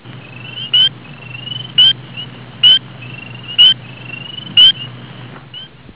Spring Peeper
Pseudacris crucifier
Their mating call is a high toned pulse sound, much like a birds peep sound, hence the name.
Same things apply for mating, only males can perform a mating call.
springpeepercallpseudacriscrucifier.au